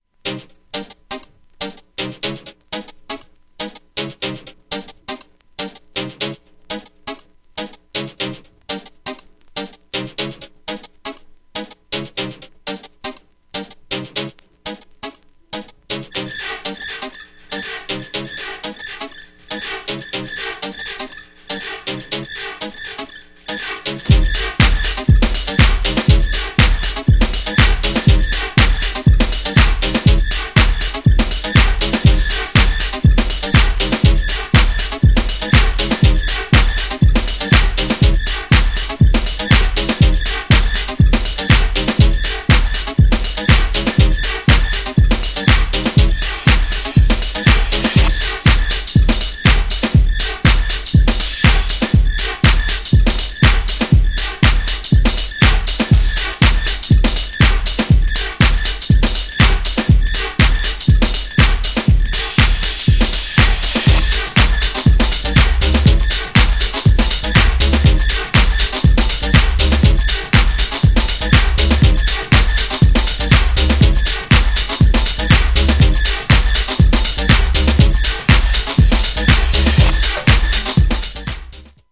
DEEPだけど弾むビートがかっこいい93年のHOUSE CLASSIC!!
GENRE House
BPM 121〜125BPM